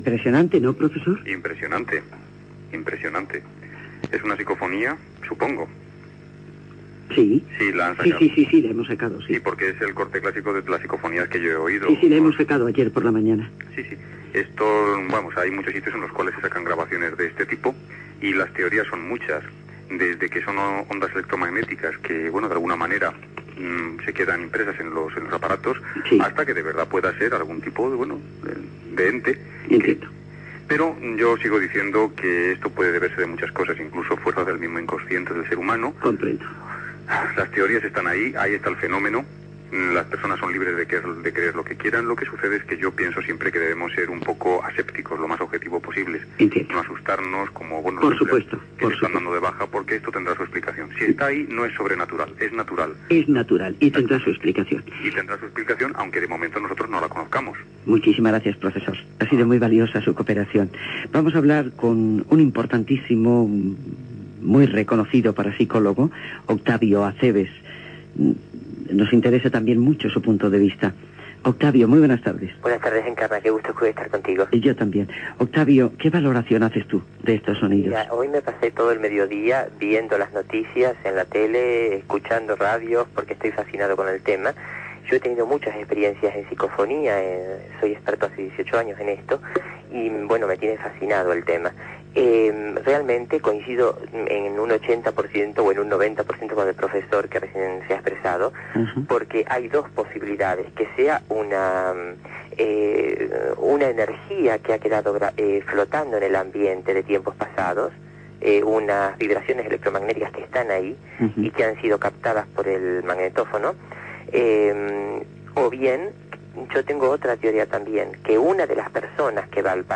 Entrevista telefònica
Entreteniment